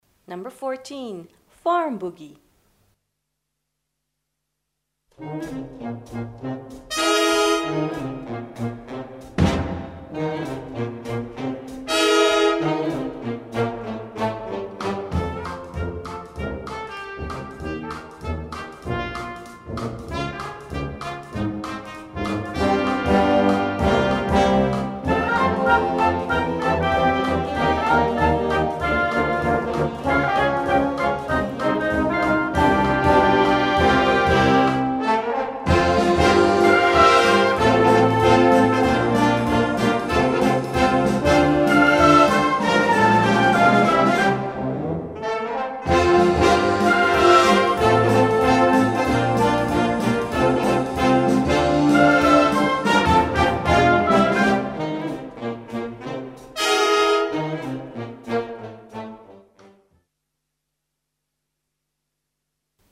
Puhallinorkesteri / Wind Orchestra